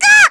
Luna-Vox_Damage_kr_02.wav